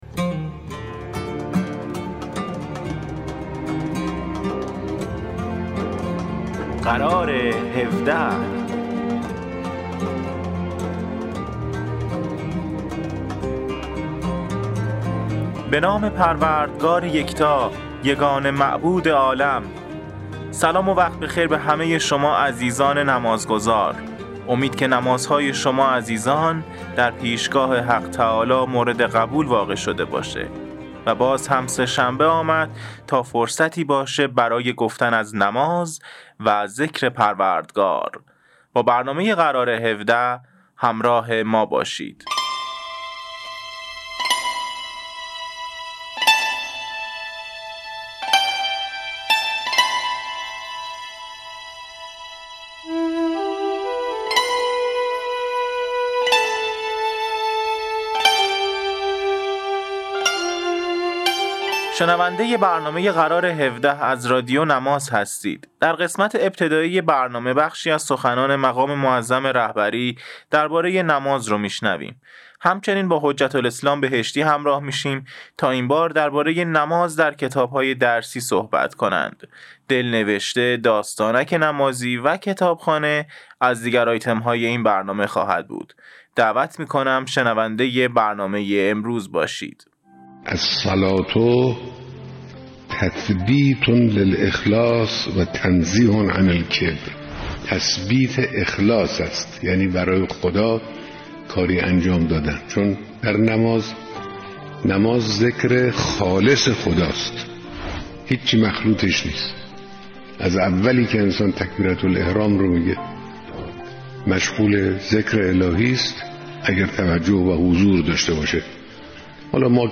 برنامه اینترنتی قرار هفده مجموعه ای از آیتم های نمازی و در مورد بخش های مختلف از نماز، دارای تواشیح، سخنرانی های نمازی، سرود و ترانه، دلنوشته، خاطرات و معرفی کتاب و … است.